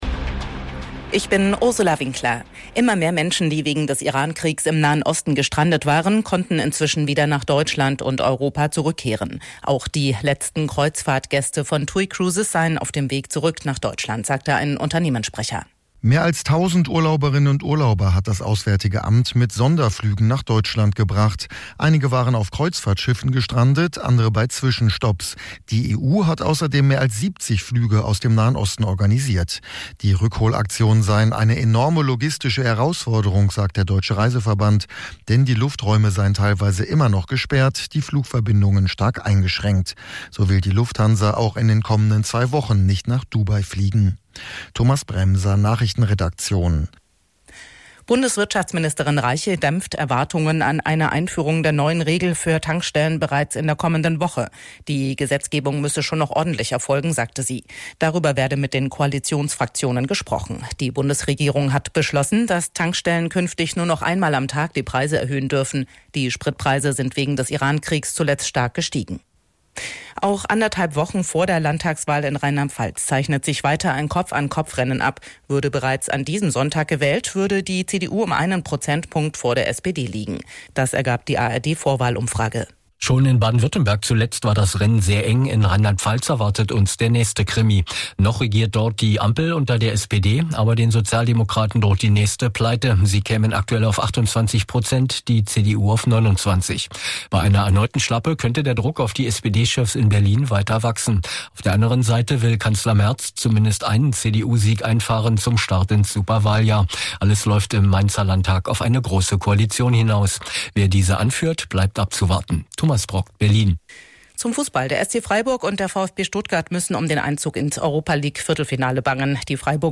Radio Hamburg Nachrichten vom 13.03.2026 um 05 Uhr